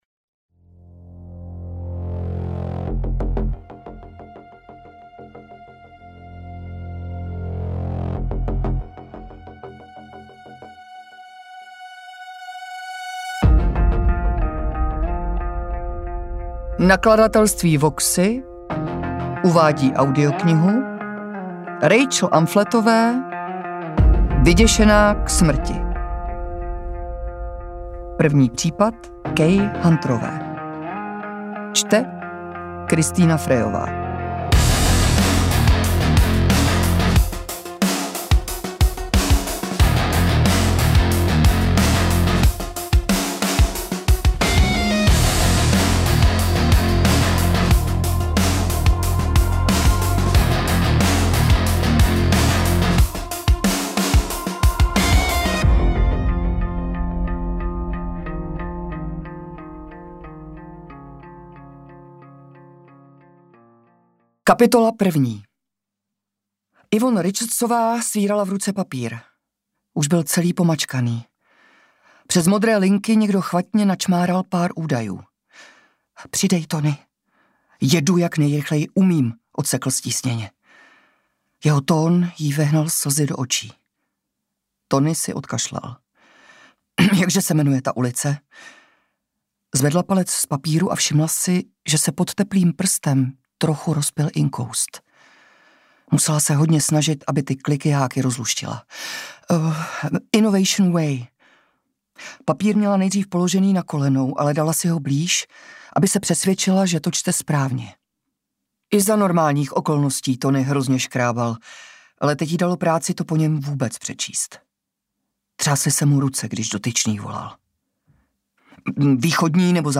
Interpret:  Kristýna Frejová